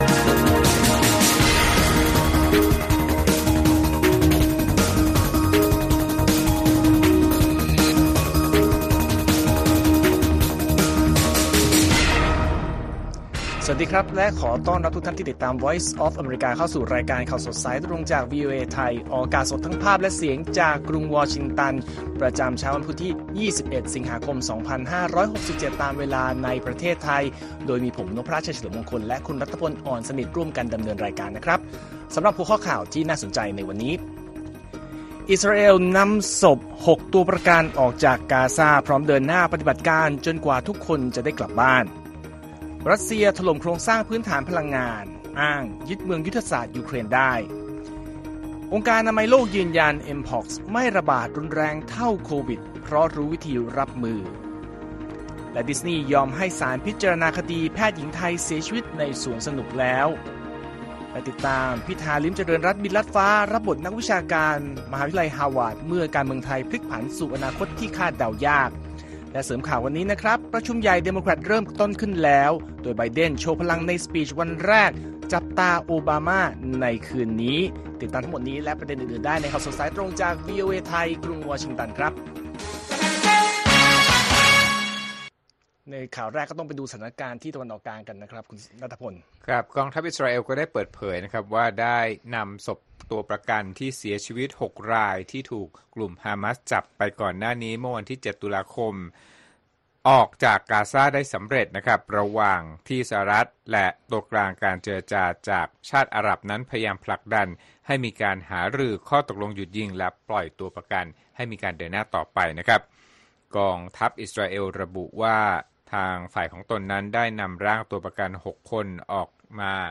ข่าวสดสายตรงจากวีโอเอไทย วันพุธ ที่ 21 ส.ค. 2567